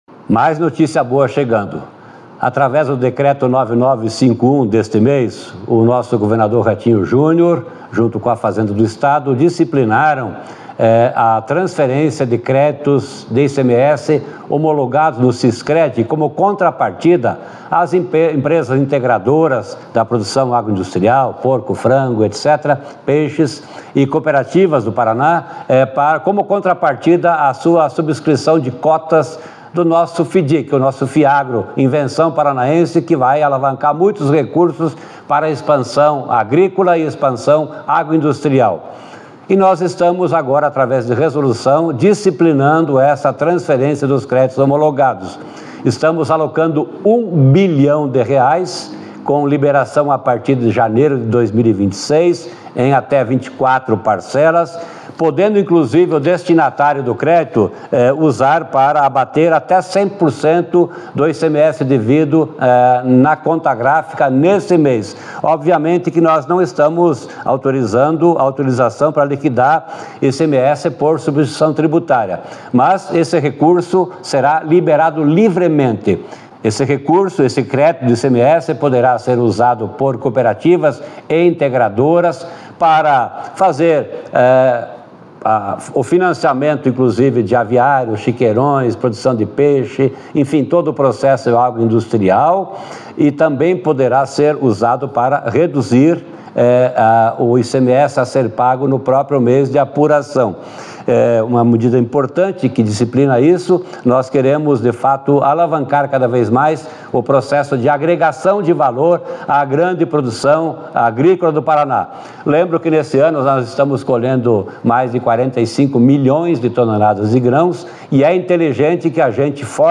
Sonora do secretário da Fazenda, Norberto Ortigara, sobre a destinação de 1 bilhão de reais em créditos de ICMS para quem investir no Fundo Agroindustrial do Paraná